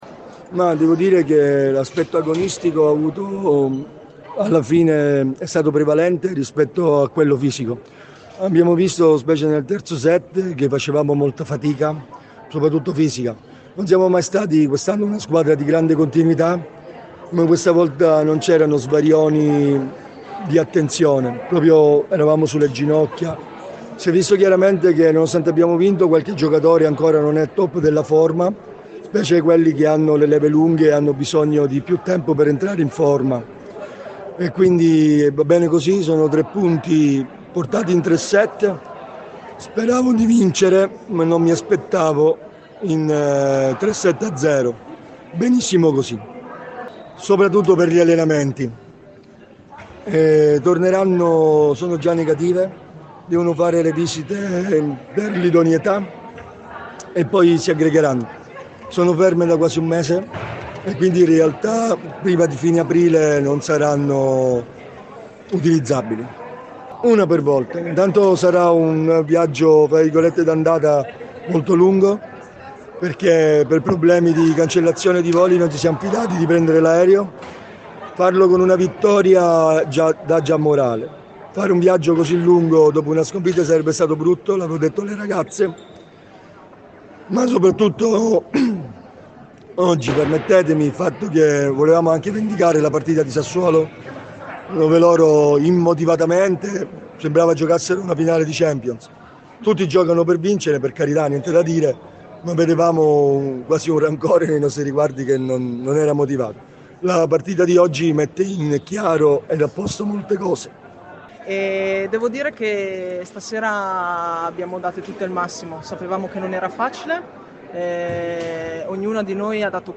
interviste-post-sassuolo.mp3